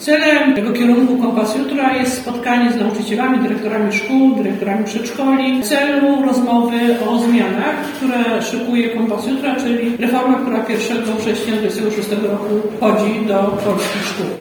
Zapewniła o tym podczas dzisiejszej wizyty w Lublinie wiceminister edukacji Katarzyna Lubnauer.
O ,,Kompasie Jutra” mówi wiceminister edukacji Katarzyna Lubnauer: